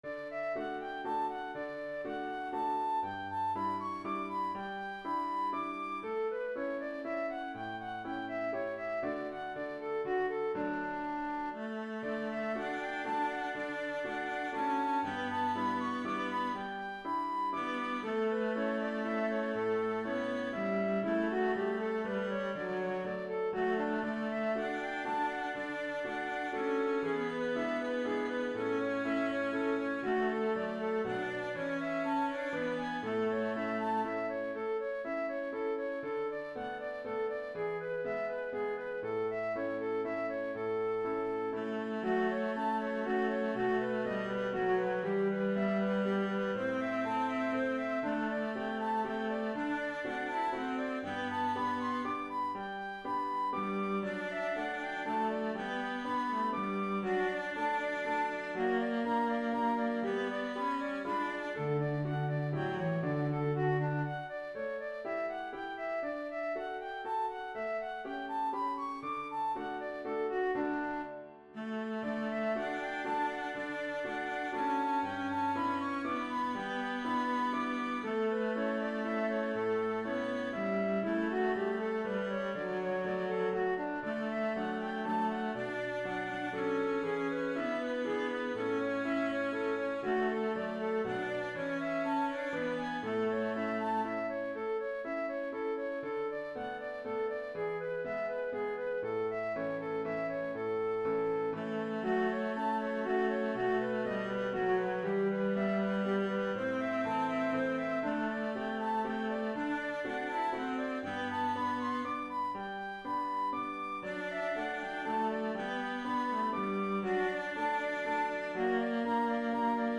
Canzonetta from Don Giovanni Mozart Flute, cello, and piano
Same as above, except piano substitutes for guitar.
A simple "oom-pah-pah" guitar part or piano part can replace the string orchestra.
Throughout, I've retained Mozart's delightful harmonization; in addition to standard triads, we find some surprising 6th, 7th, and diminished chords.
canzonetta-F-Vc-Pf.mp3